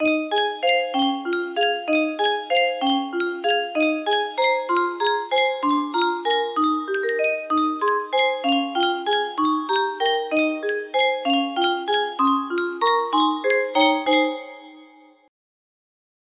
Yunsheng Music Box - Little Boy Blue 2799 music box melody
Full range 60